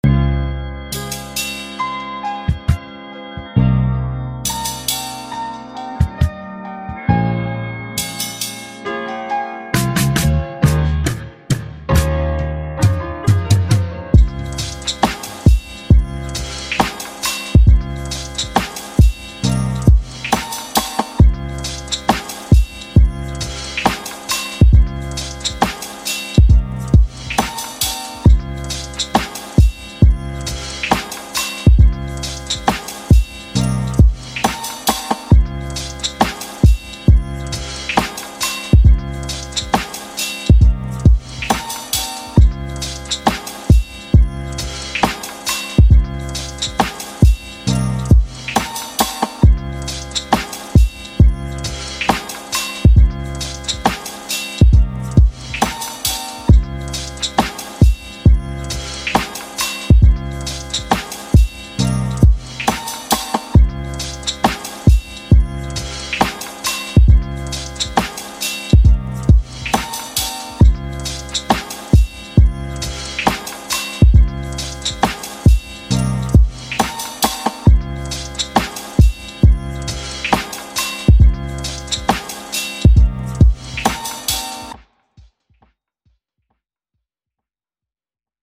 68/136 BPM
hip hop boom bap type beat lofi instrumental rap 90s vintage
pretty relaxed